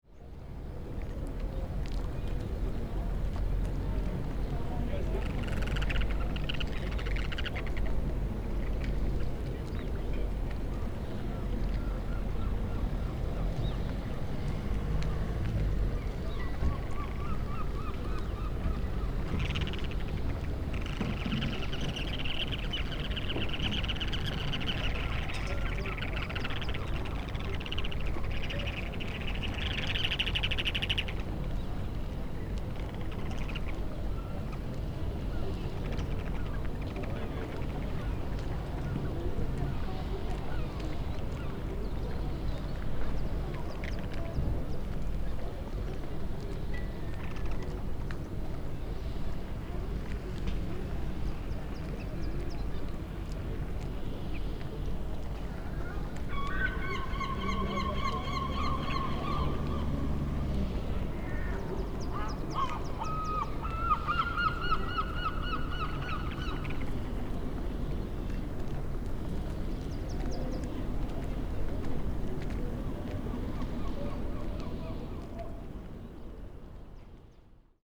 Turnstones
On the quay at La Criée, turnstones are chattering away!
As for the seagull, it is always present… more than just a sound associated with Lesconil, it is THE omnipresent marker !